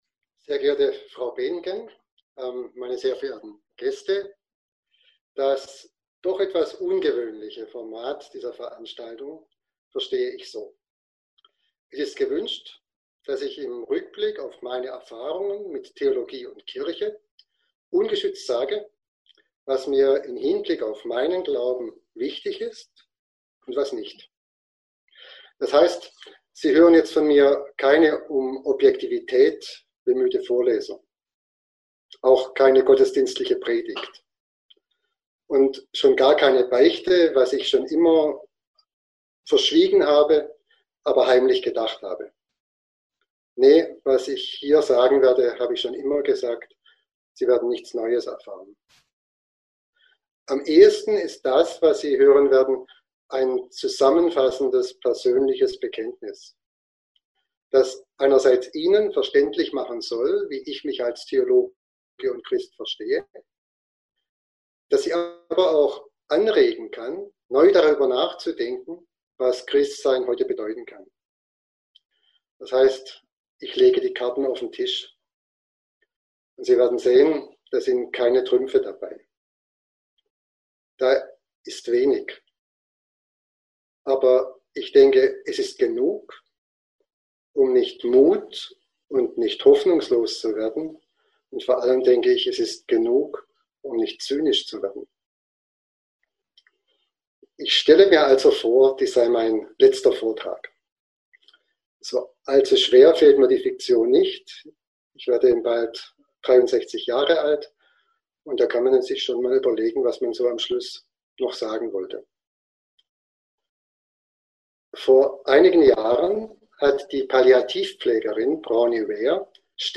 Die Online Veranstaltung findet über die Software Zoom unter strikter Einhaltung des Datenschutzes statt.
Last_Lecture_1.mp3